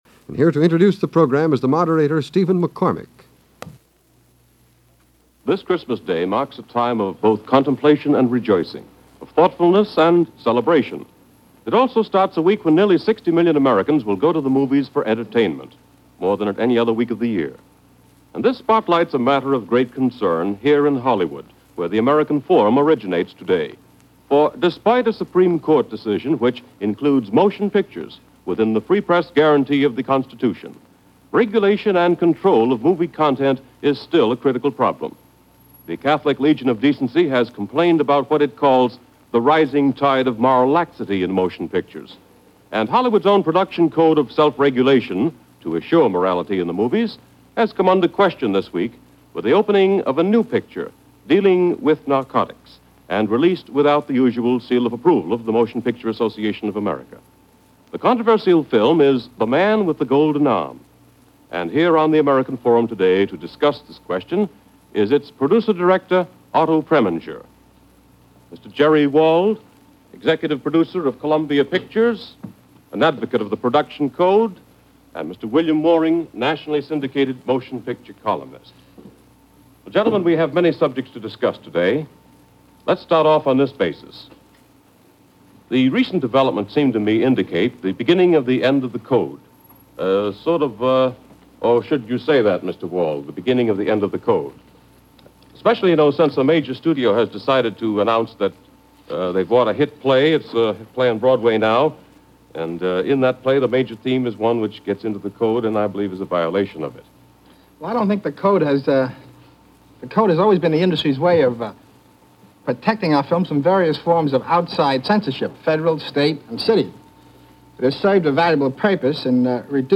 To discuss the issue, on this segment of The American Forum, first aired on December 25, 1955, the film’s director Otto Preminger faces critics and studio head Jerry Wald over the issue of Movies and Morality and where The Man With The Golden Arm stood in all of it. 60 years later, the world and the movies in it have changed.